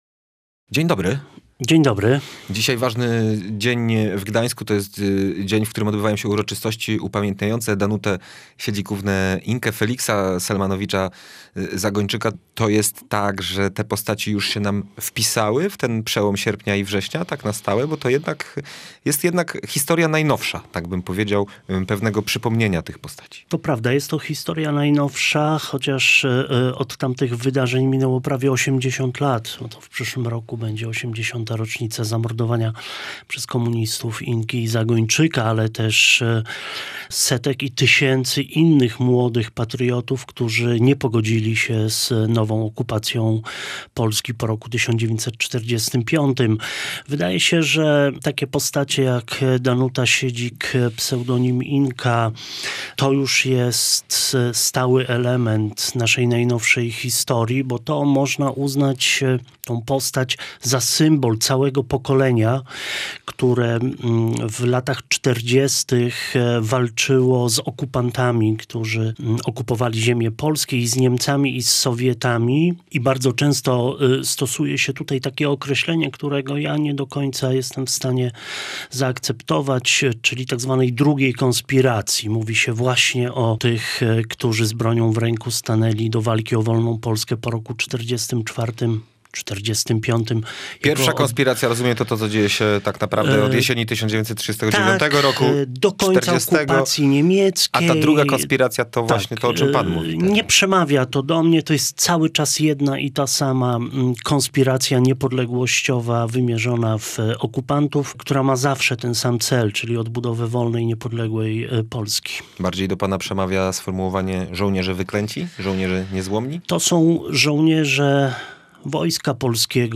Gdańszczanin mówił w Radiu Gdańsk, że z Karolem Nawrockim współpracował bardzo blisko.